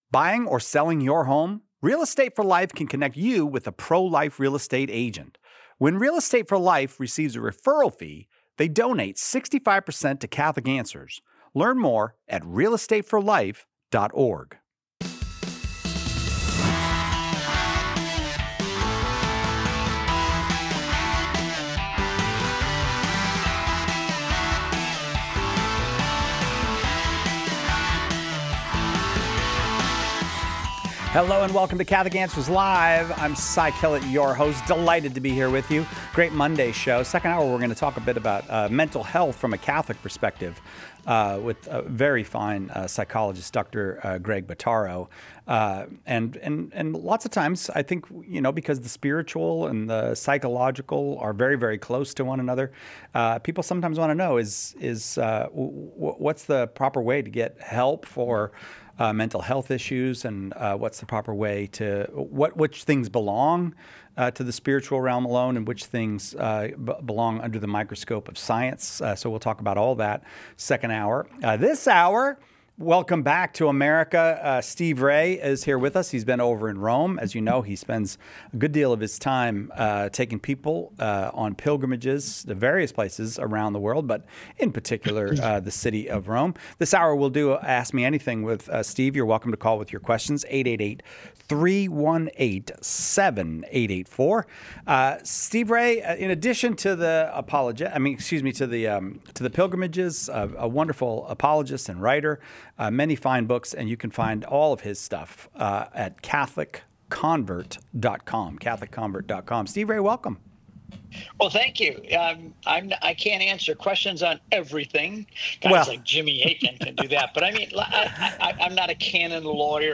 In this episode of Catholic Answers Live , we tackle a wide range of thought-provoking questions from callers seeking clarity on Catholic teaching and practice. The highlight question: Can a Catholic date a non-denominational Christian—and make it work spiritually and relationally?